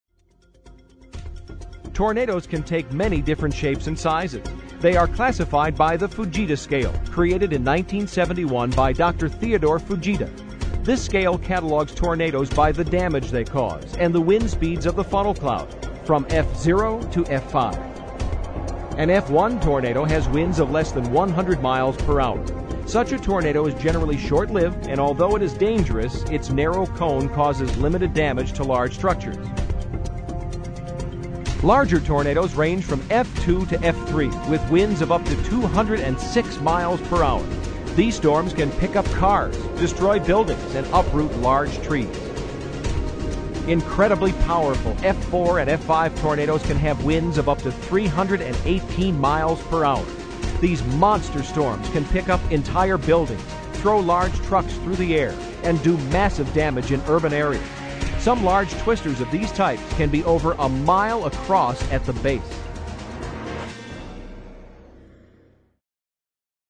available in 5.1 surround sound or stereo